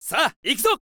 私は矢印のサインが出た時に「いくぞ！」という音声にしています。
わかりやすいですし、気分も乗ってきそうです。